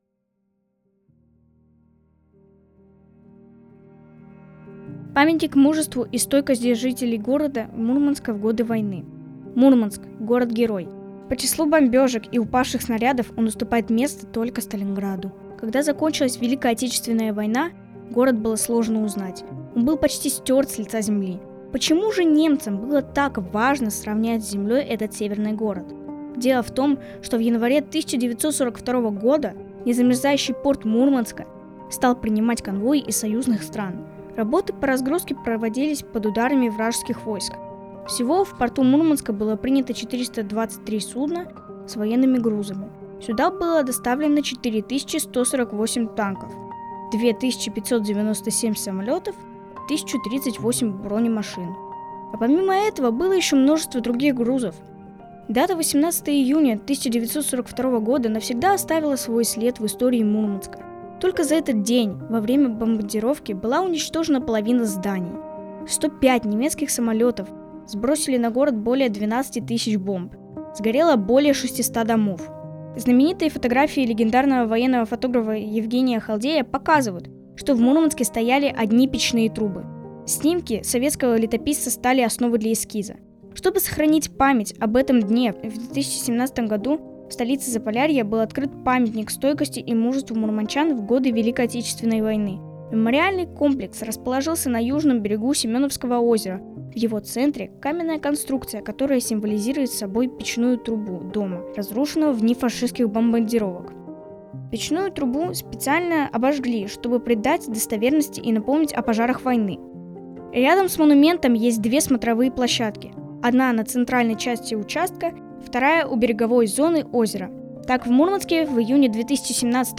Представляем новую аудиоэкскурсию, подготовленную волонтерами библиотеки в рамках туристического проекта «51 история города М»